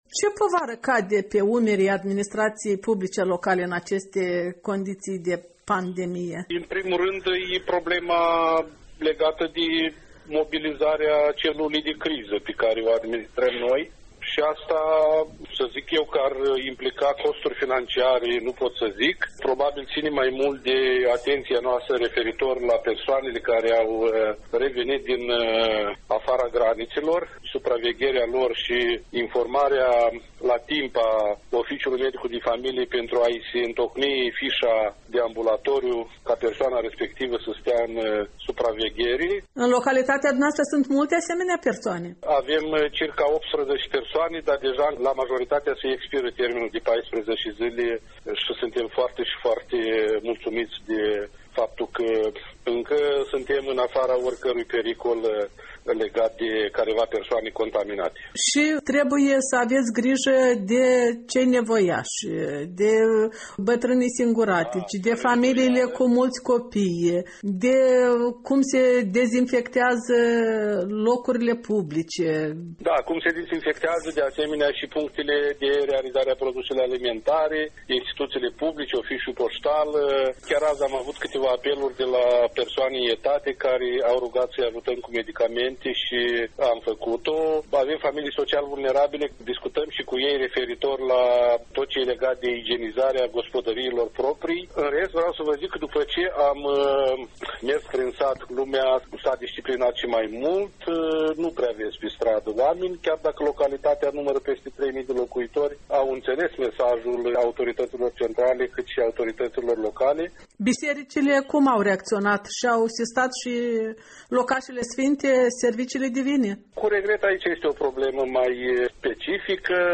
Interviu cu primarul Nicolae Tudoreanu